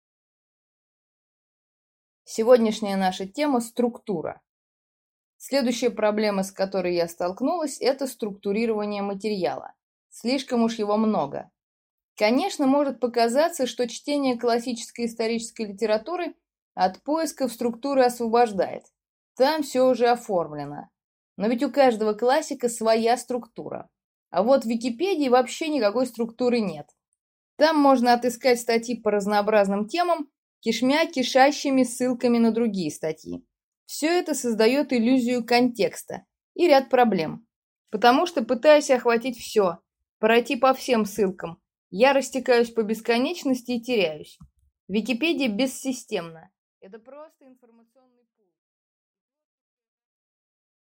Аудиокнига Структура. Мир через призму Империй | Библиотека аудиокниг